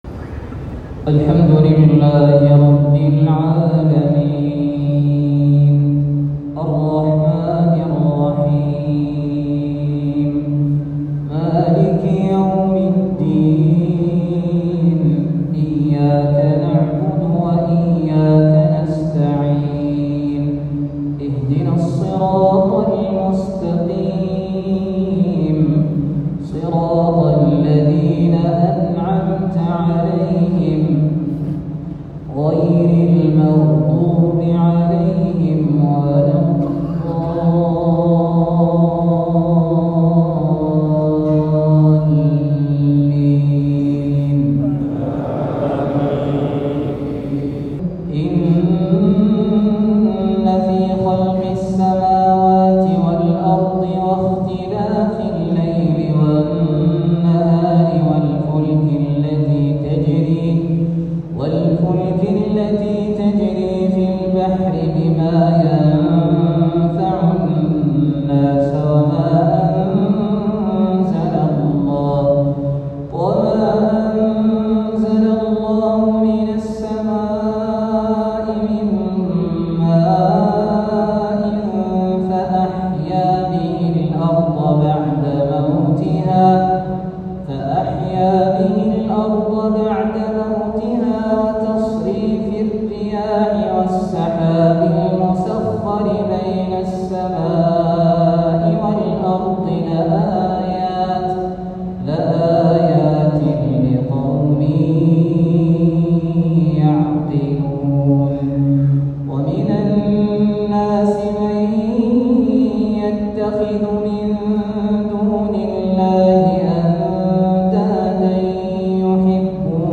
عشائية الجمعة بمسجد قباء